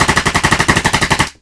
9MM UZI.WAV